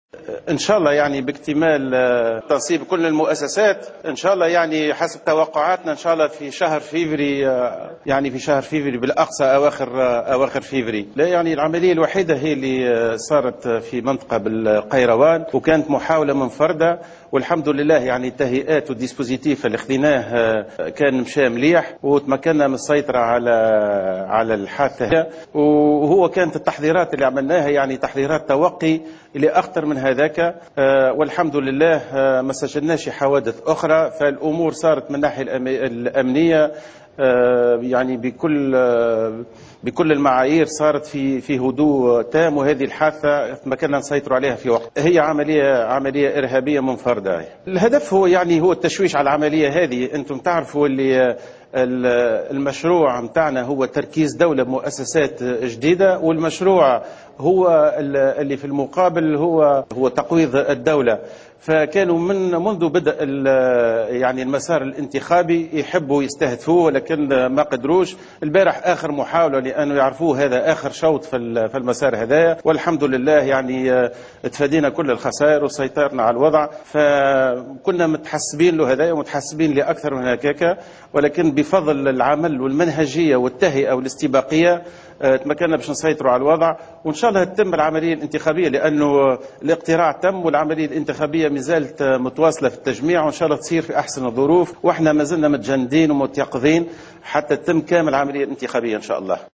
قال رئيس الحكومة مهدي جمعة في تصريح اعلامي مساء اليوم الأحد 21 ديسمبر 2014 إن عملية اطلاق النار على جندي بمعتمدية حفوز من ولاية القيروان كانت عملية الارهابية منفردة الهدف منها التشويش على الإنتخابات وتقويض الدولة لكن الاستعدادات التي اتخذتها وزارة الداخلية مكنت من السيطرة على هذه الحادثة وفق قوله.